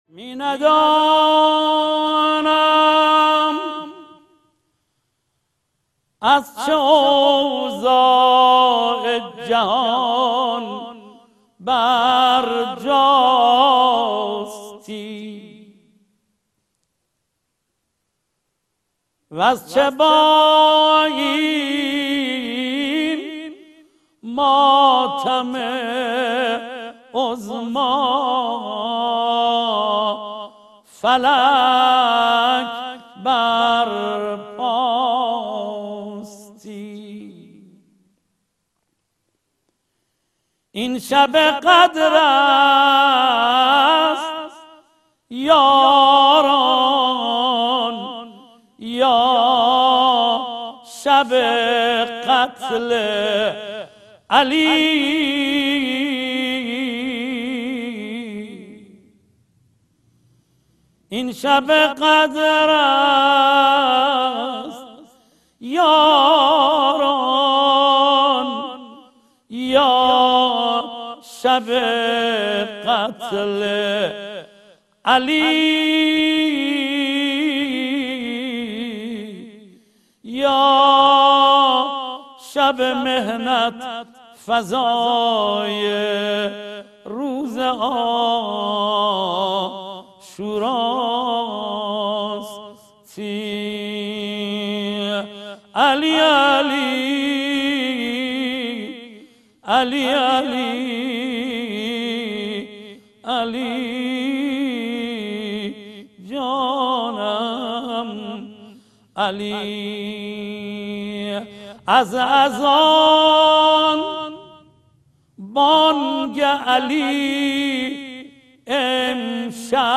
فرازی از ذکر مصیبت